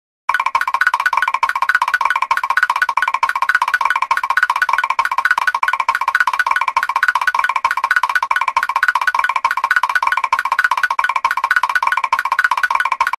walk.wav